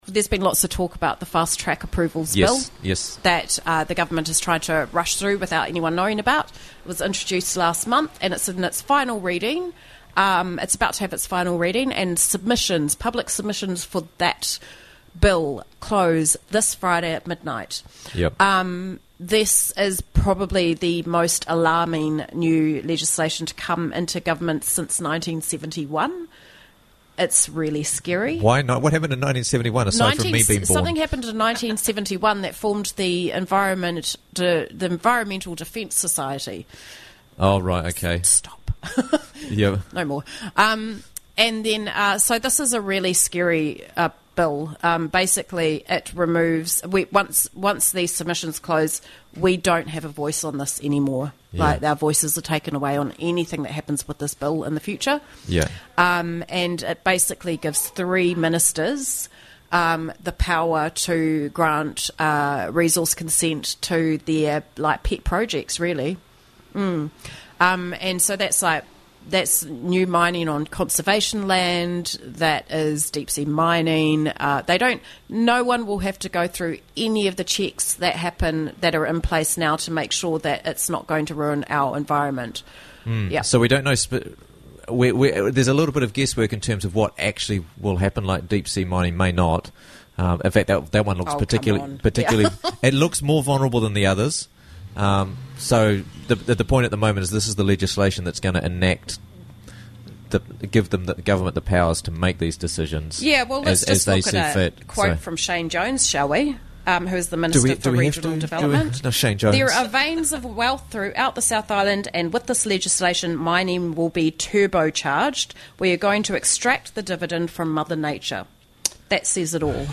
Submitting On The Fast Tracks Approvals bill - Interviews from the Raglan Morning Show